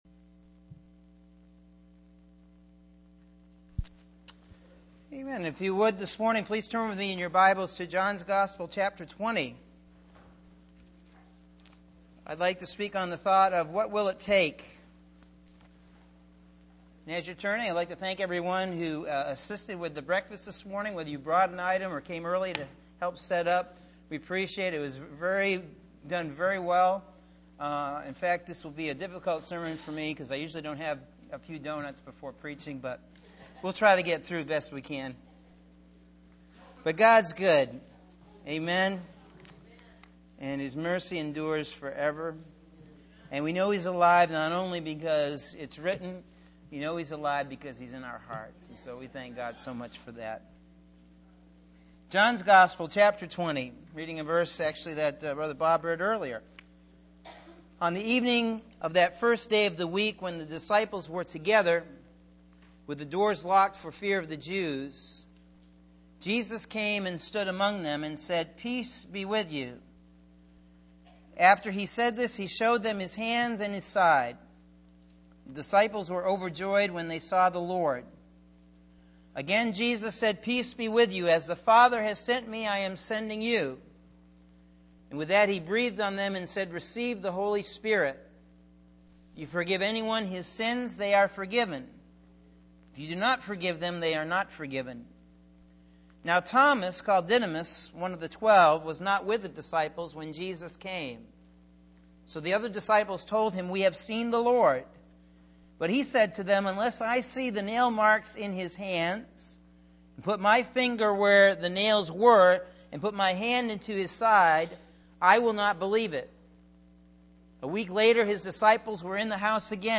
Sunday April 4th – AM Sermon – Norwich Assembly of God